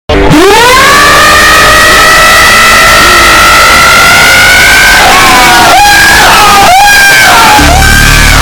Bird Scream Power Up Meme
Play Bird Scream Power Up Meme Sound Button For Your Meme Soundboard!